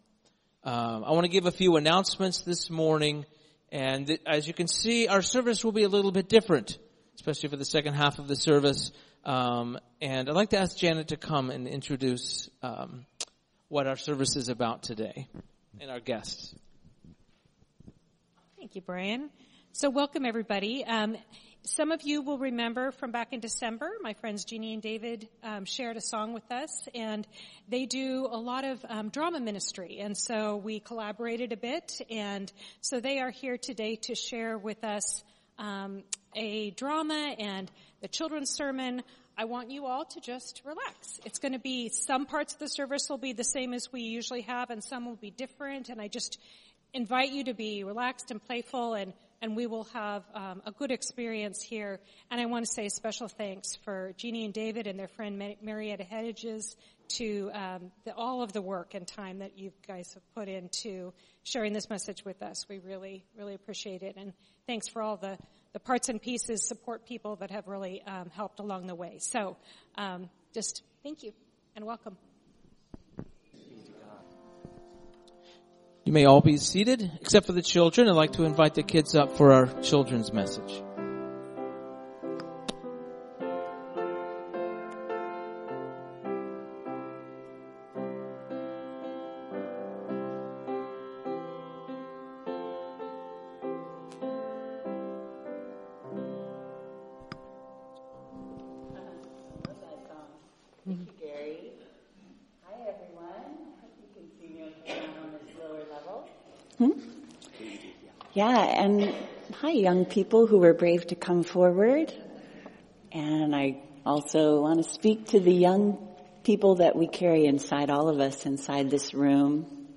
Listen as we had a dramatic performance.